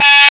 Index of /90_sSampleCDs/USB Soundscan vol.01 - Hard & Loud Techno [AKAI] 1CD/Partition D/14-PERCS
PERC     2-L.wav